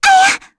Lavril-Vox_Damage_kr_01.wav